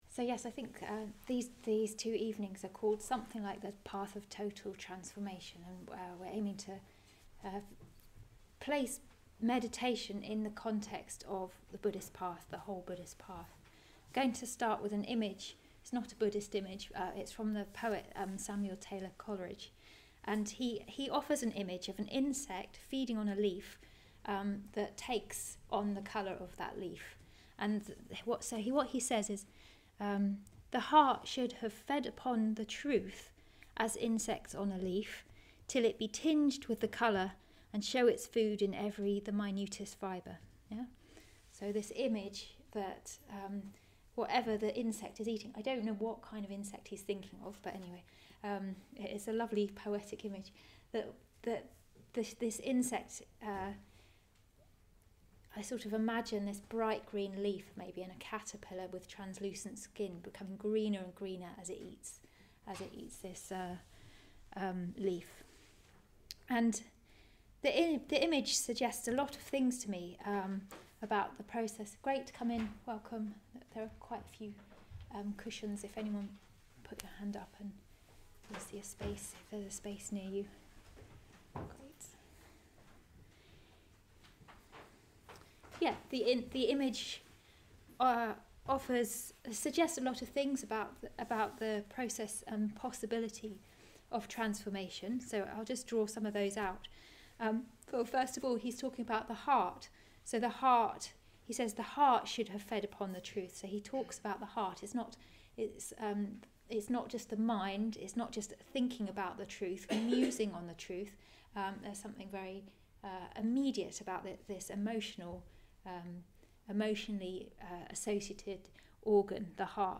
giving a talk during the meditation challenge on the threefold path of ethics, meditation and wisdom.